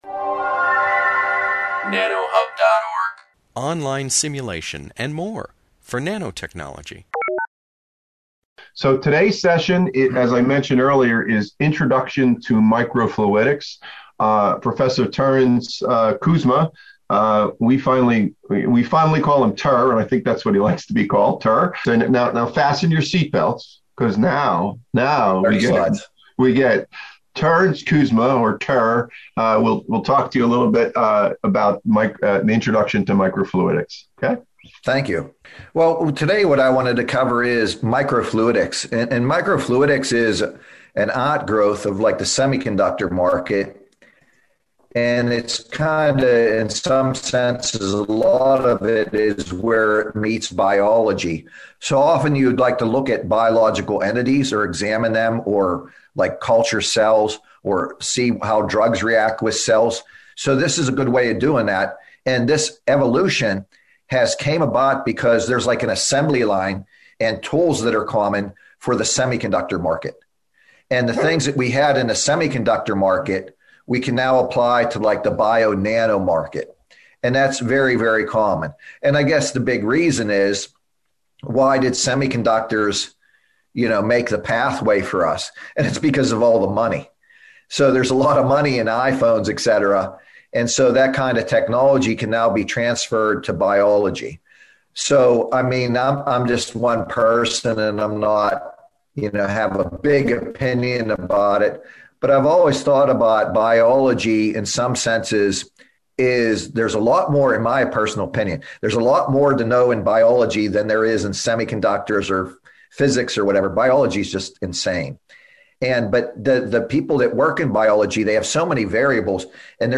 Audio/Visual -- Voice Recording Instructional Material -- Lecture/Presentation